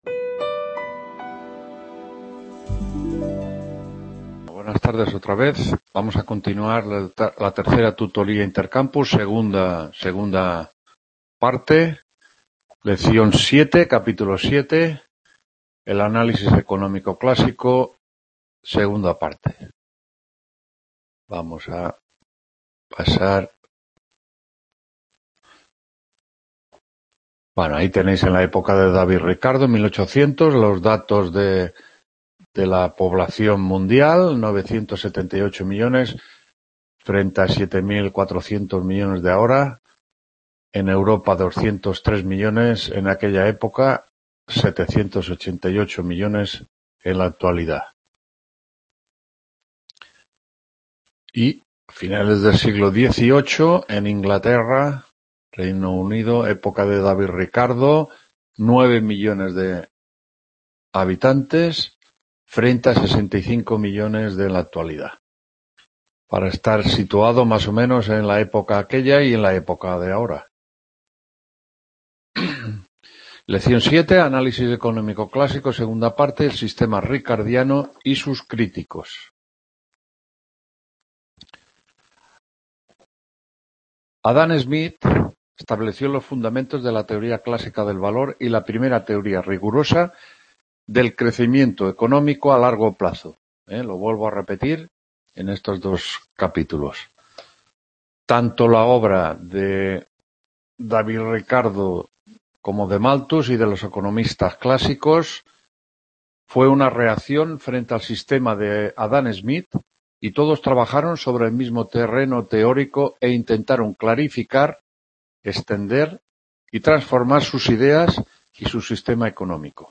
3ª TUTORÍA INTERCAMPUS (y II) HISTORIA PENSAMIENTO… | Repositorio Digital